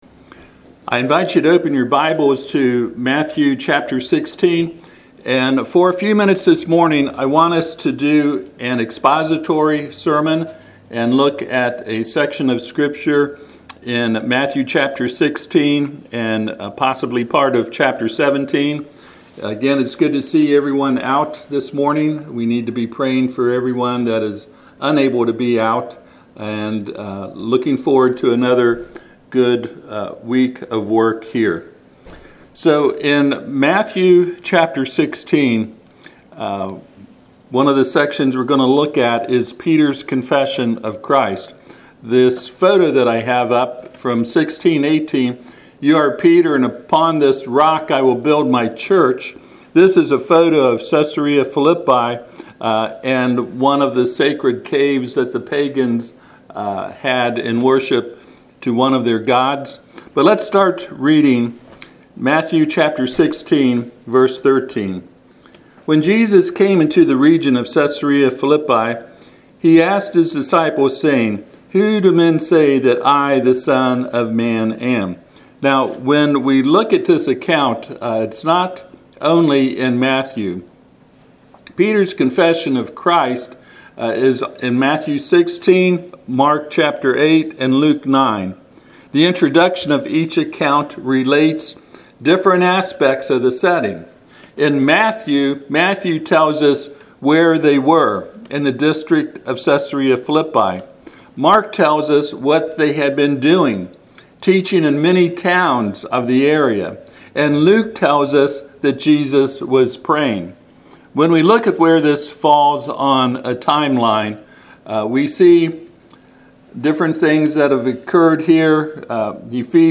Passage: Matthew 16:13-18 Service Type: Sunday AM